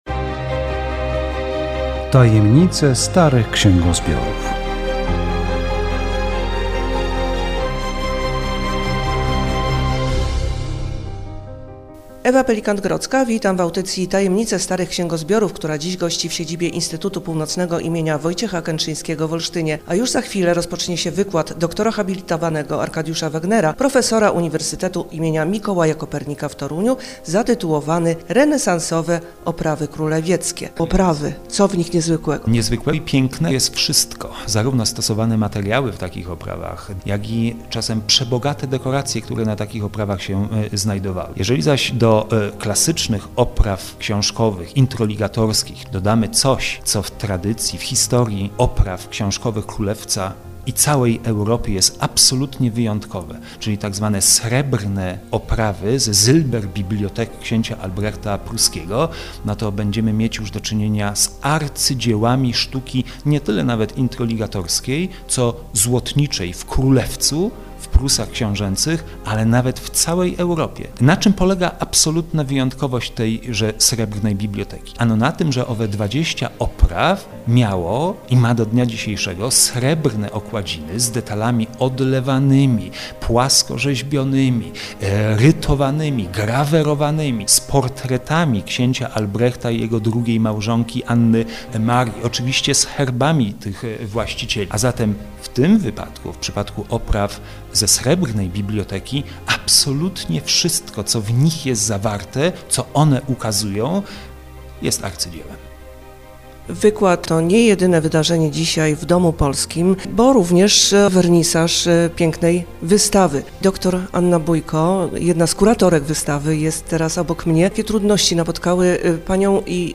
Audycja radiowa